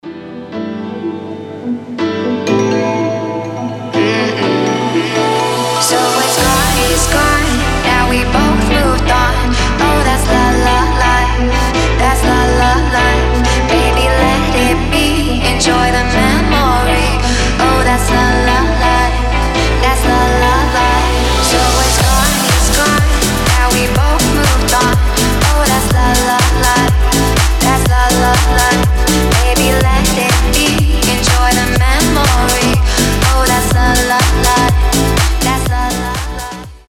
deep house
Dance Pop
красивый женский голос
Мелодичная танцевальная музыка